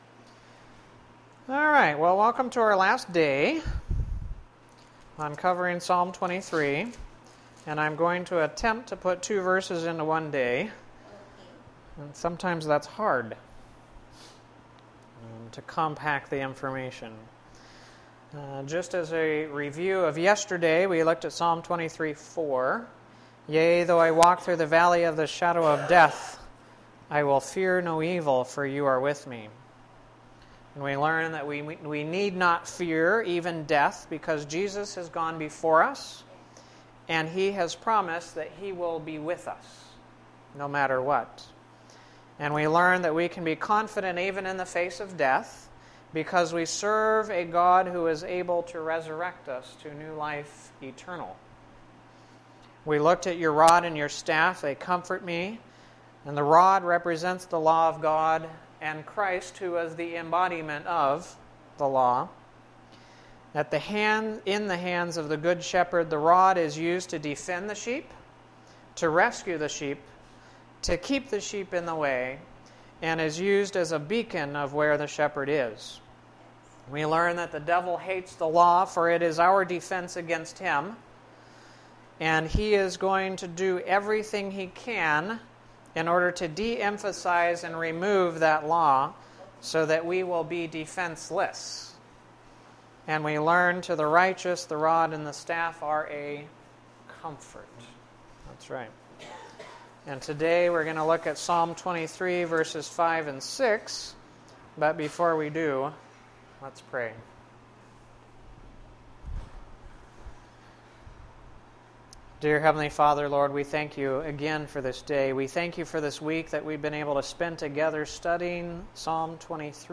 This is the 5th of 5 presentations based upon Psalm 23 which were done at Community Hospital during the week of January 10-14, 2011. These thoughts come from Psalm 23:5 & 6.